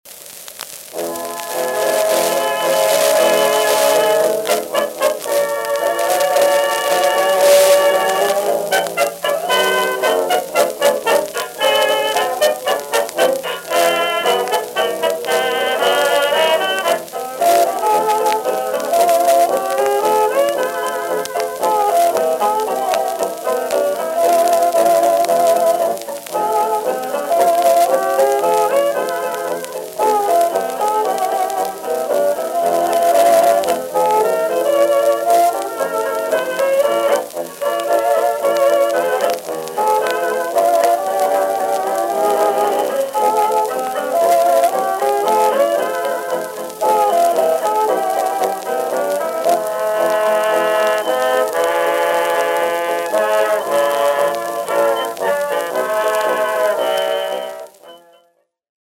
Flat de-rumble 3.0Mil Elliptical